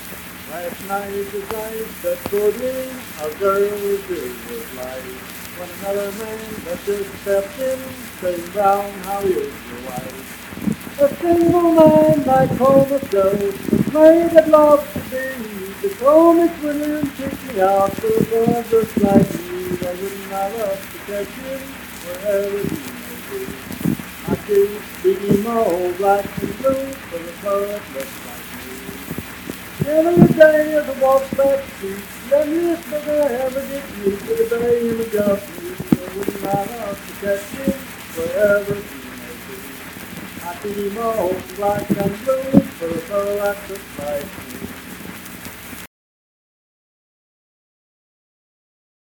Unaccompanied vocal music performance
Verse-refrain 9(6w/R).
Voice (sung)